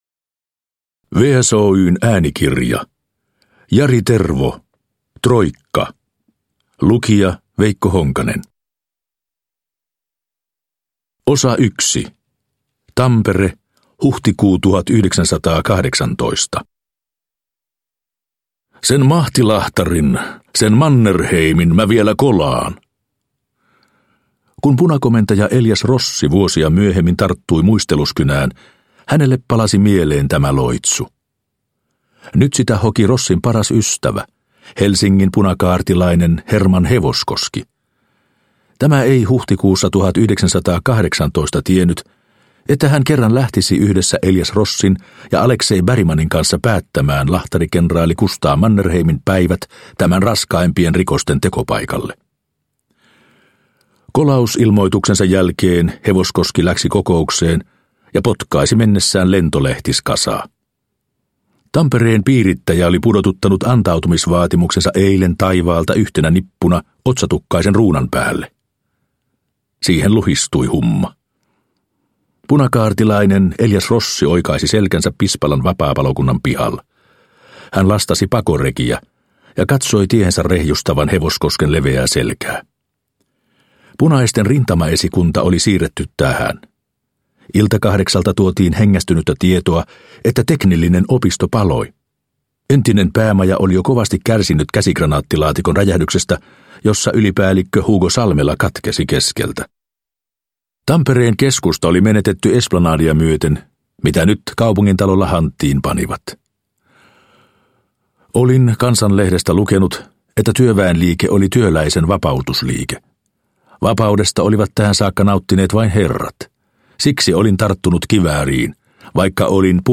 Troikka – Ljudbok – Laddas ner